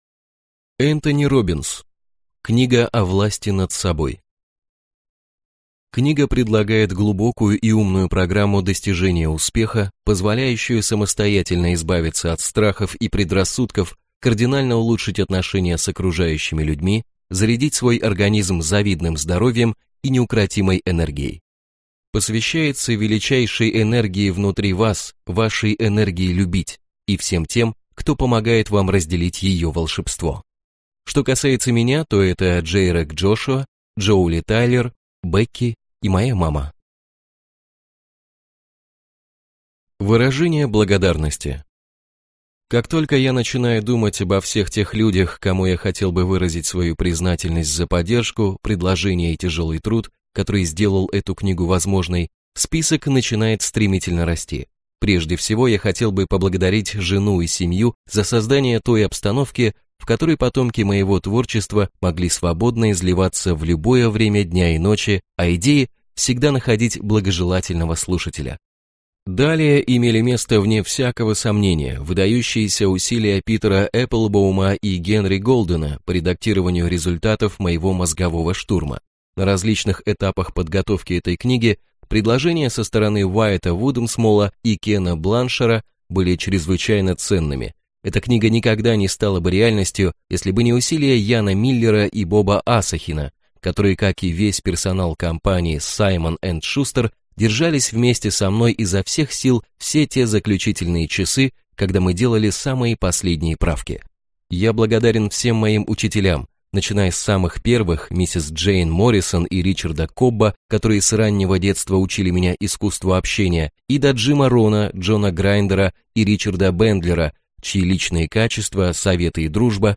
Аудиокнига «Книга о власти над собой» в интернет-магазине КнигоПоиск ✅ в аудиоформате ✅ Скачать Книга о власти над собой в mp3 или слушать онлайн